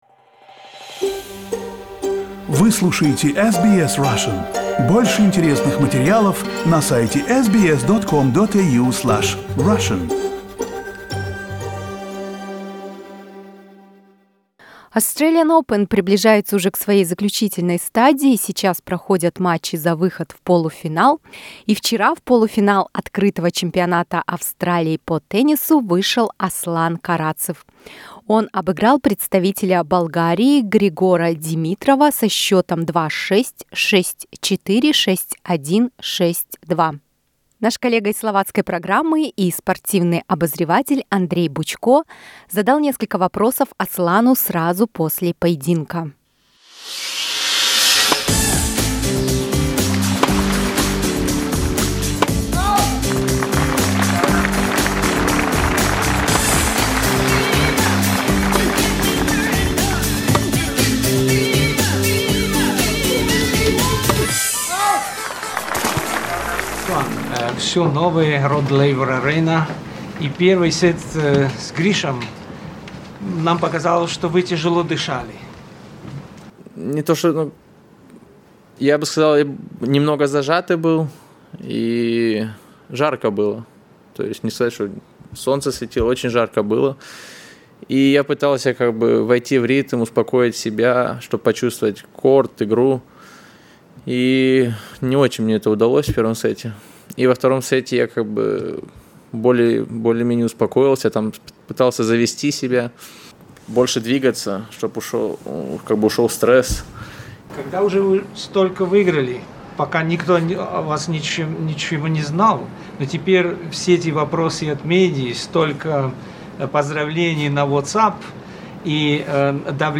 Interview with a Russian tennis player Aslan Karatsev after getting into semi-finals at Australian Open.